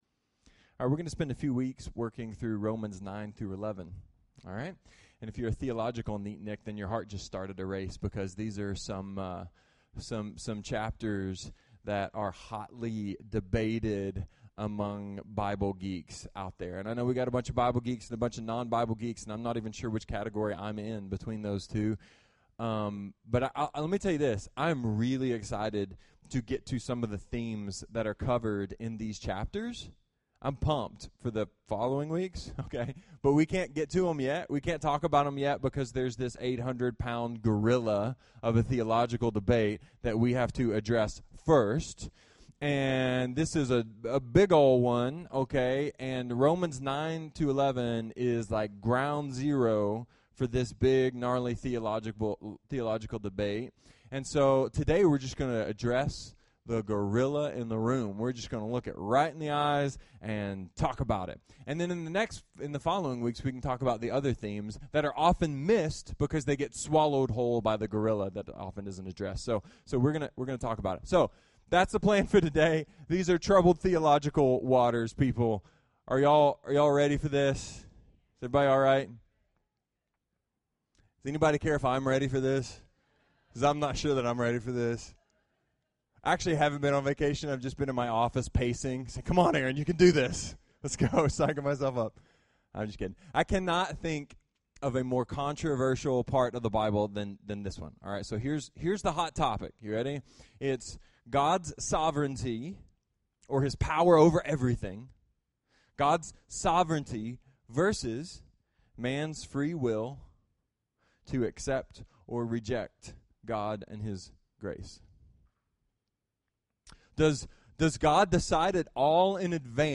A sermon about lids that are too small, yellow balls, and the sovereignty of God.